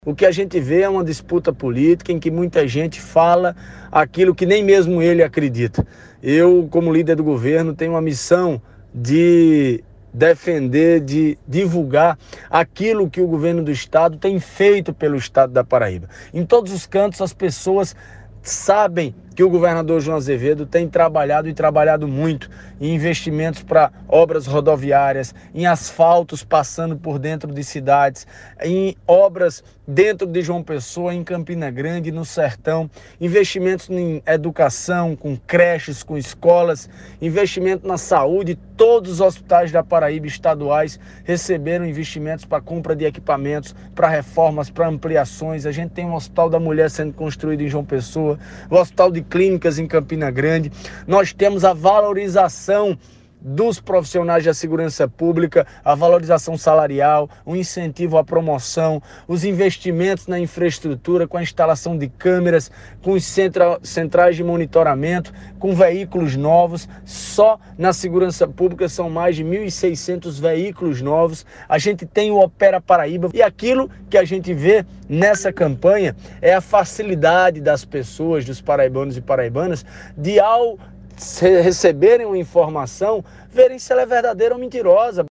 O comentário do parlamentar foi registrada pelo programa Correio Debate, da 98 FM, de João Pessoa, nesta quinta-feira (08/09).